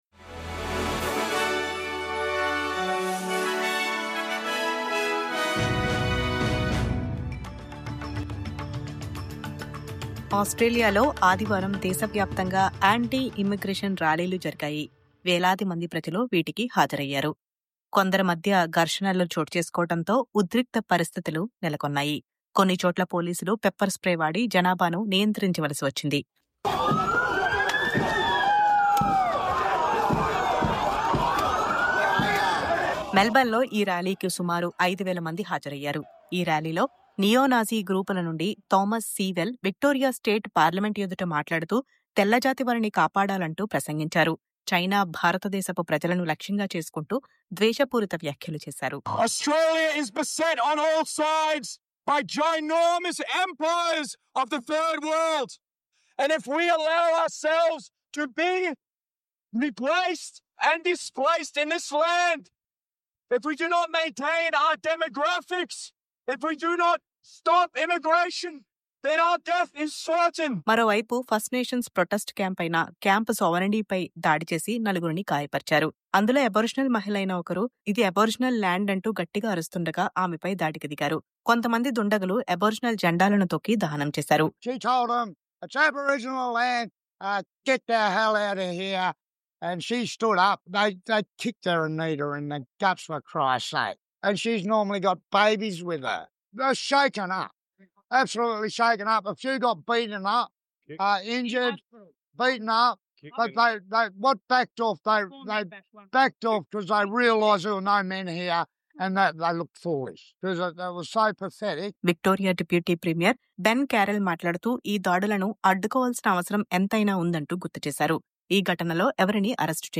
News update - దేశవ్యాప్తంగా anti-immigration ర్యాలీలు... ఉద్రిక్తతలు, అరెస్టులు..